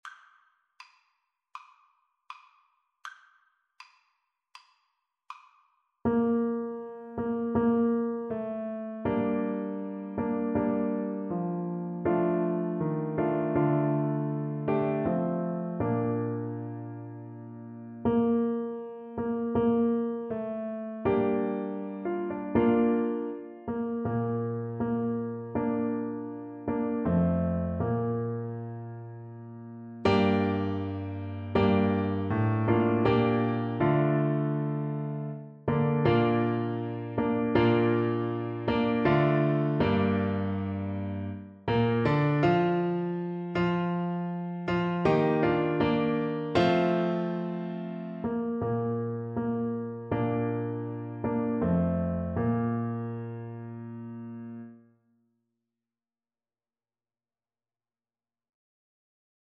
Play (or use space bar on your keyboard) Pause Music Playalong - Piano Accompaniment transpose reset tempo print settings full screen
Trumpet
Steal Away is a spiritual from the African American tradition,
4/4 (View more 4/4 Music)
Andante
Arrangement for Trumpet and Piano